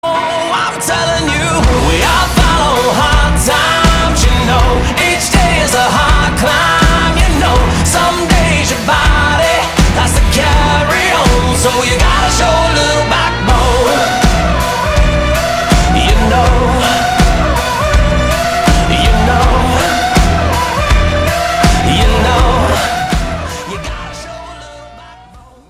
• Качество: 192, Stereo
заводные
Pop Rock